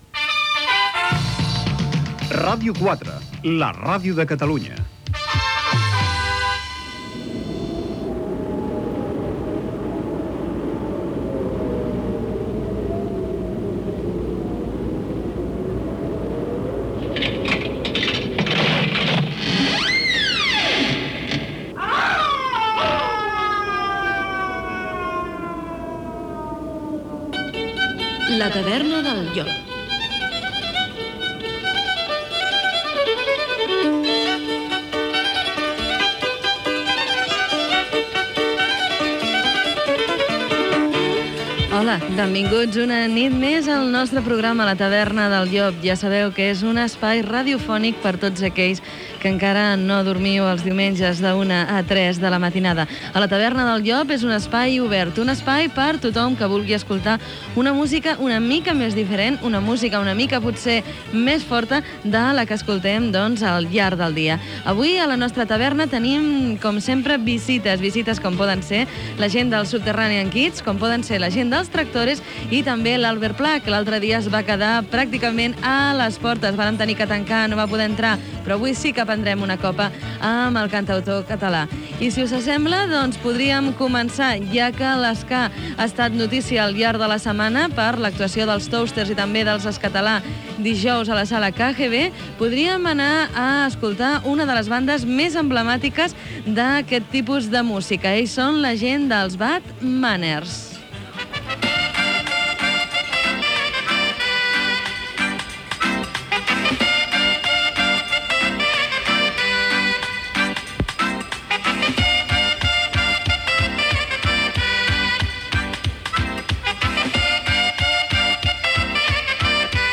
Indicatiu Ràdio 4, careta de "La taverna del llop" i inici del programa amb el sumari de continguts i un tema musical.
Musical
FM